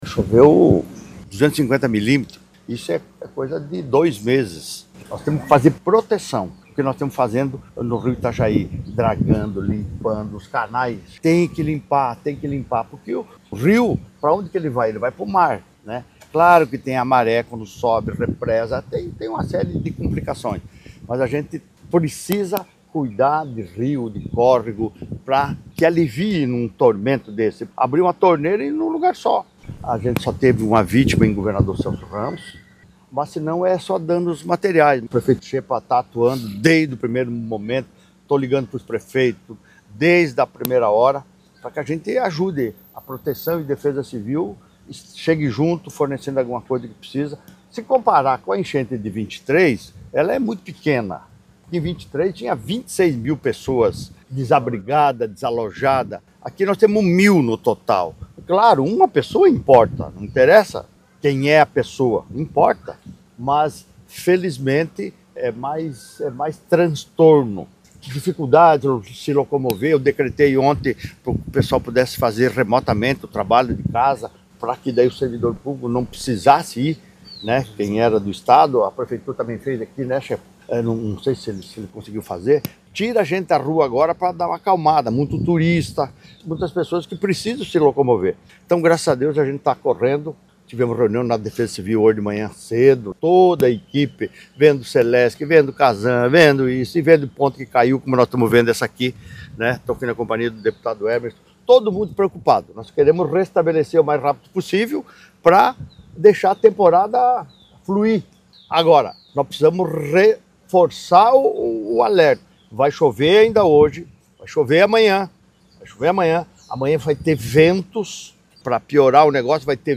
SECOM-Sonora-Jorginho-Mello-acompanha-estragos-da-chuvas-em-Itapema.mp3